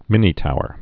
(mĭnē-touər)